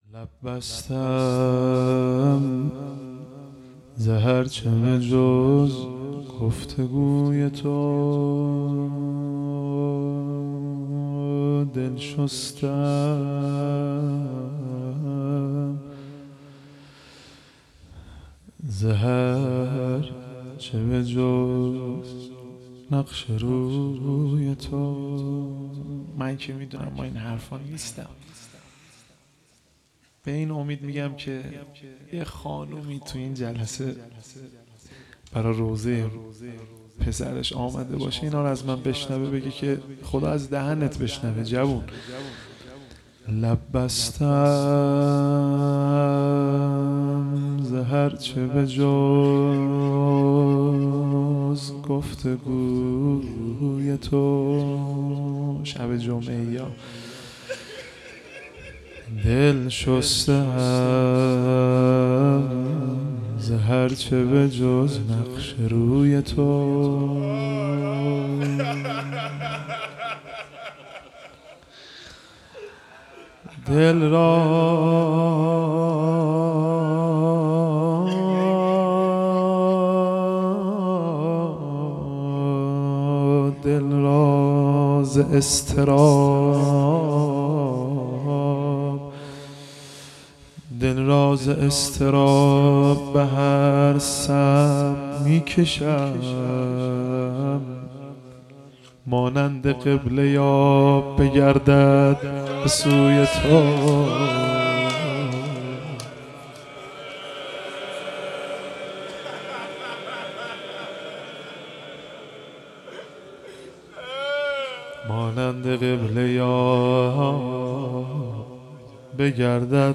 مناجات